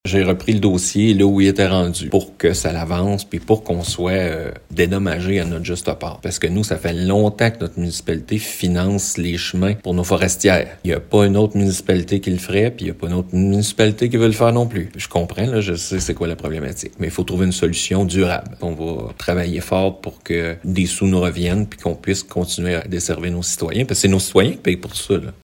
Le maire, Pierre Côté, maintient la même direction que le précédent conseil municipal sur ce dossier :